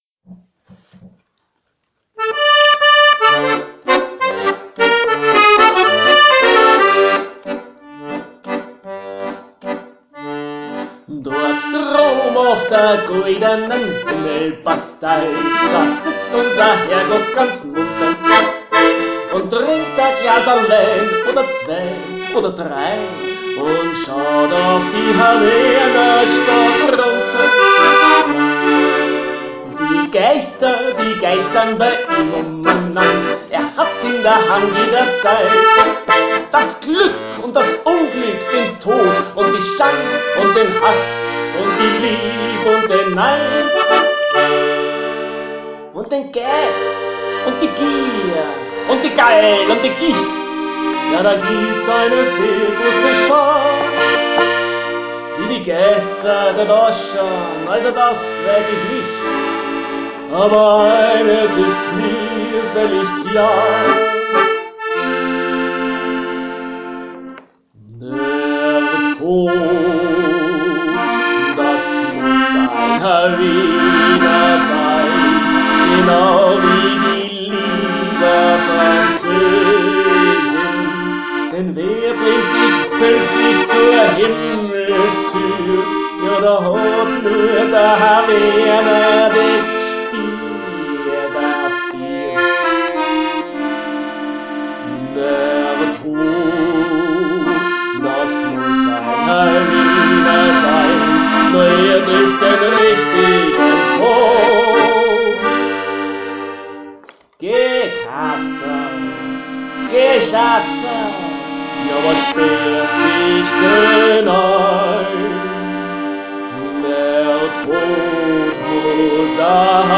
Musikprogramm mit Akkordeon und Gesang (und evt. E-Piano)
Schwarze Wienerlieder